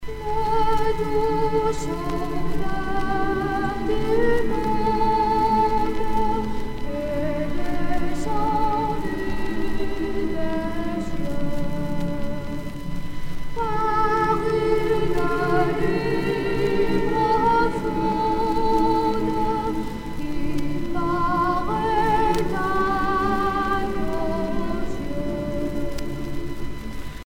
circonstance : Noël, Nativité
Genre strophique